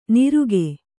♪ niruge